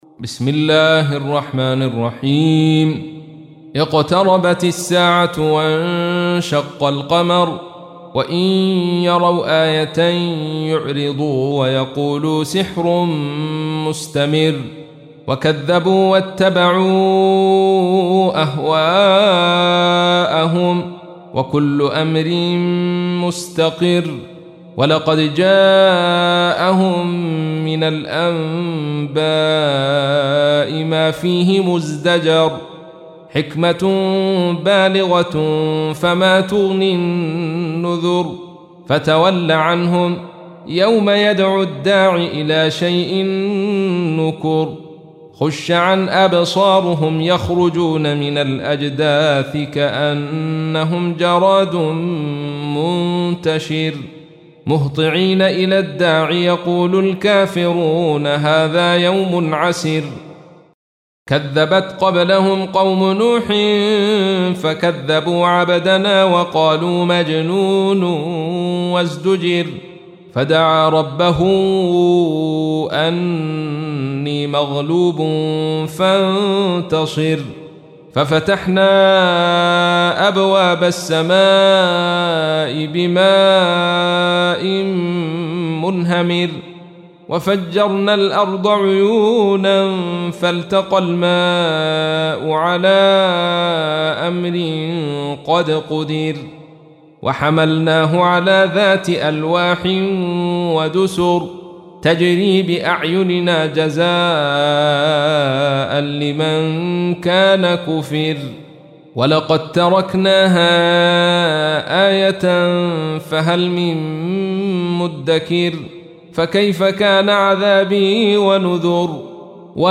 تحميل : 54. سورة القمر / القارئ عبد الرشيد صوفي / القرآن الكريم / موقع يا حسين